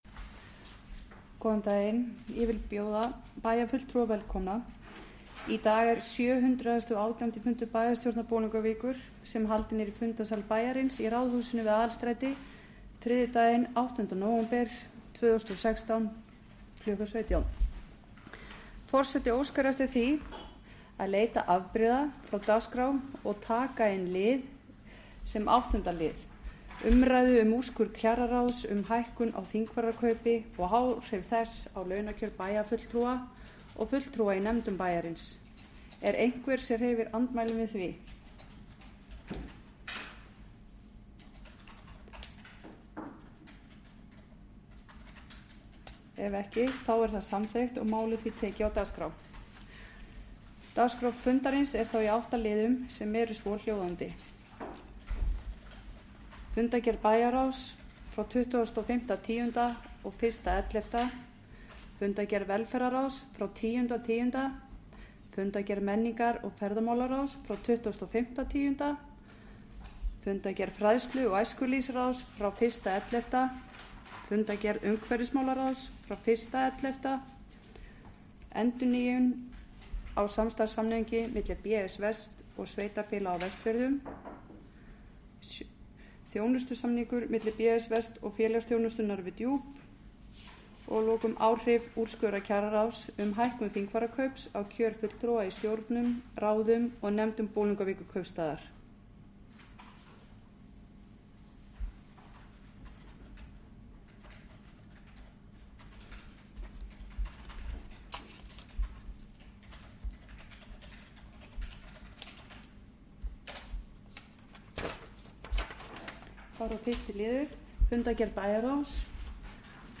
718. fundur bæjarstjórnar